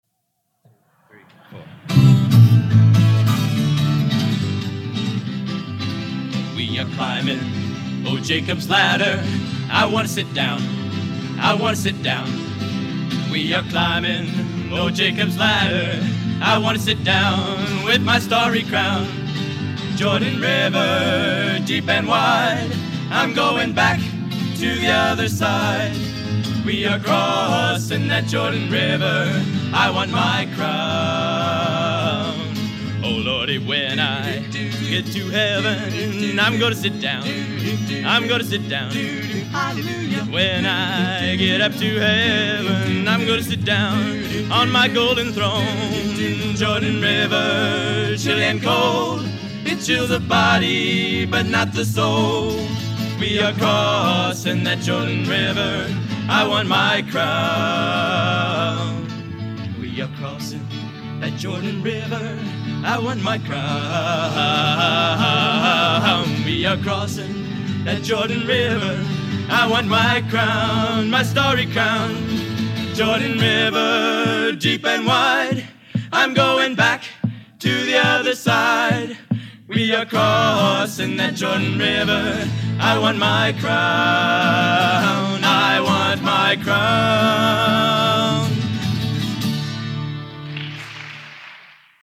Genre: Sacred Spiritual | Type: End of Season
Raucous Trio